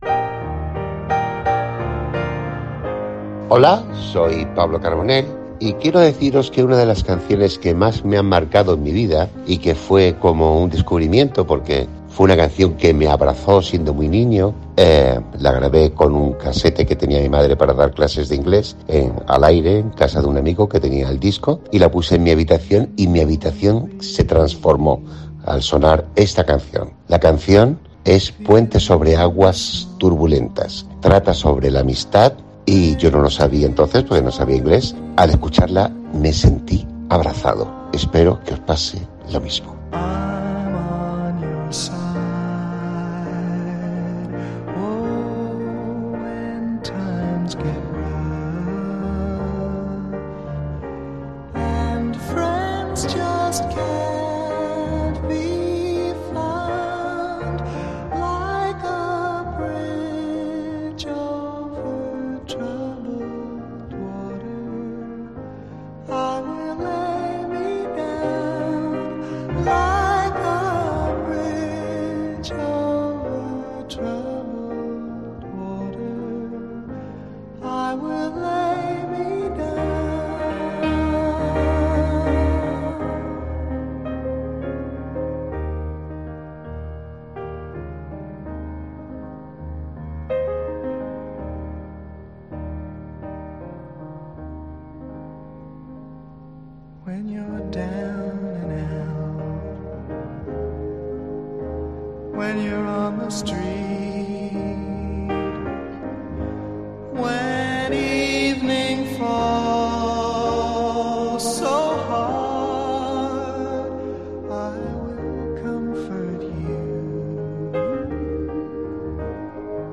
En Buenas Tardes Málaga, invitamos a Pablo Carbonell a que nos cuente cual es su canción favorita.